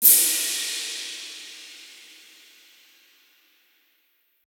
Crashes & Cymbals
808MafiaCrash.wav